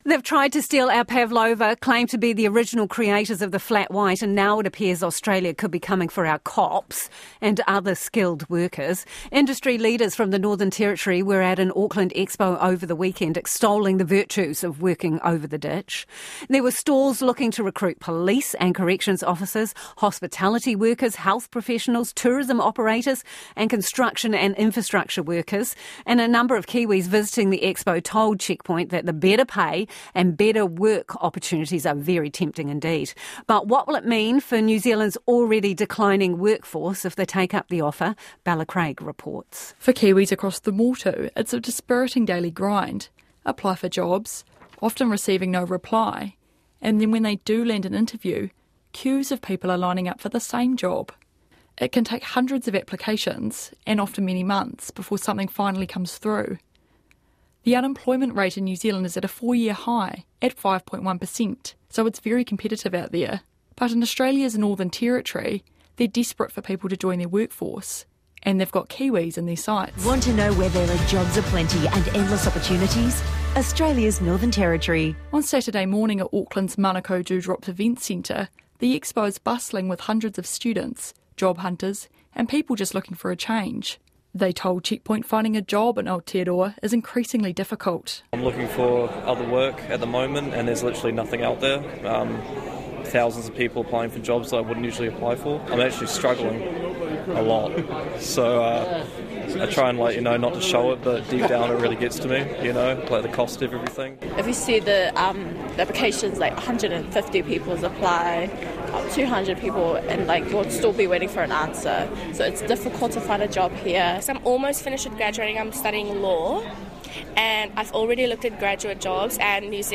29 July 2025 / Interview